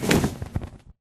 Sound / Minecraft / mob / enderdragon / wings4.ogg
wings4.ogg